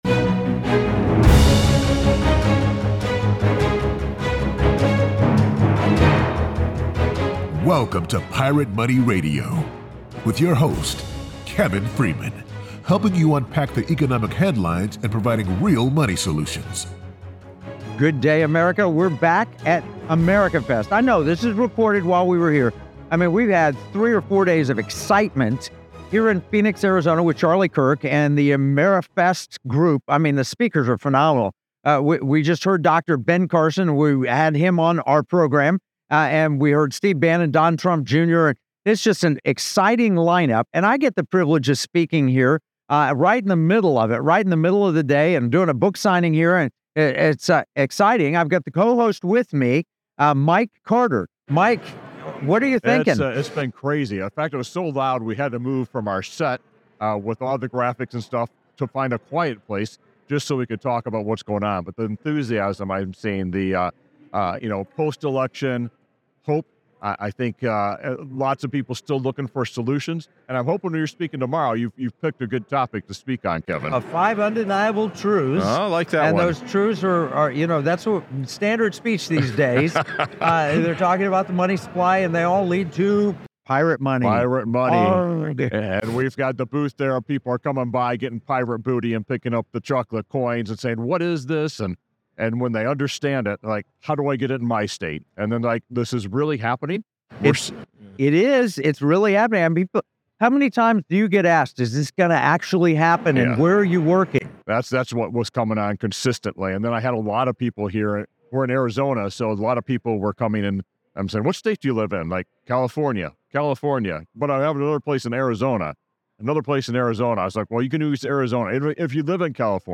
Don't miss our engaging interviews and thought-provoking discussions on what makes America resilient and poised for growth.
AMFEST 2024